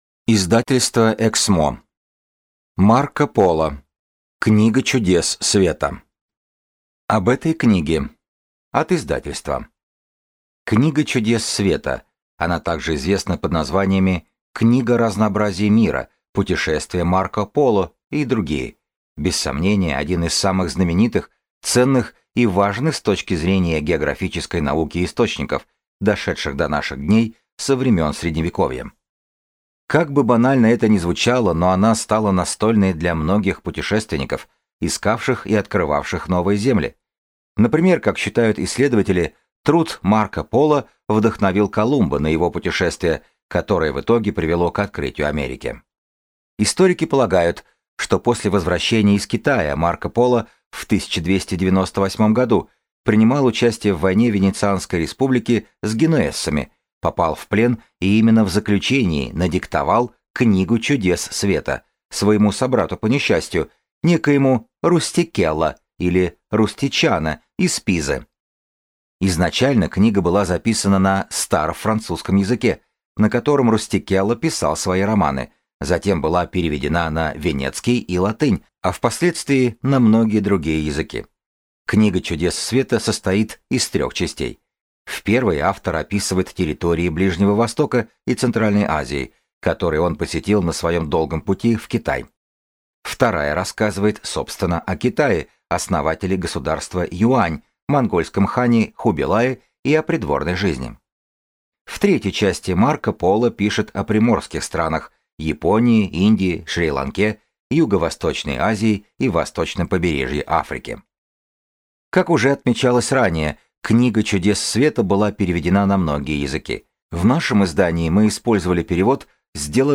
Аудиокнига Книга чудес света | Библиотека аудиокниг